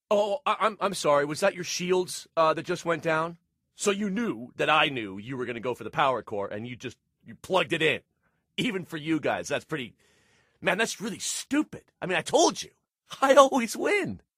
Shields went down rant